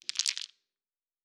Dice Shake 6.wav